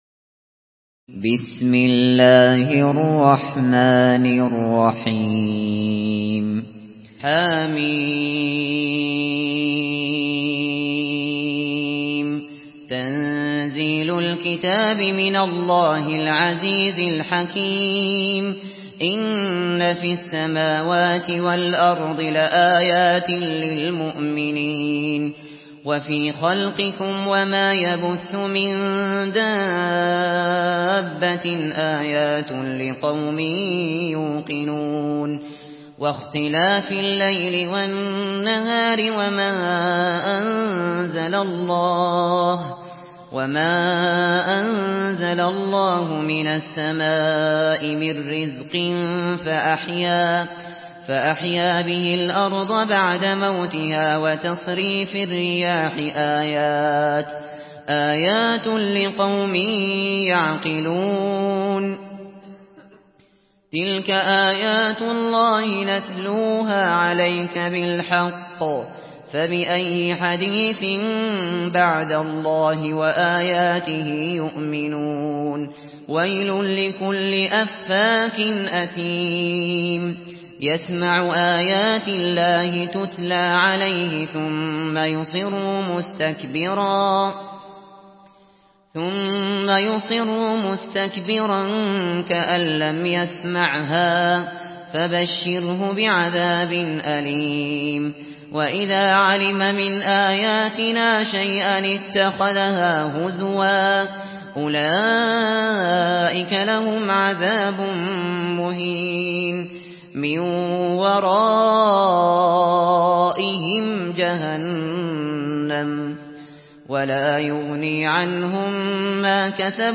تحميل سورة الجاثية بصوت أبو بكر الشاطري
مرتل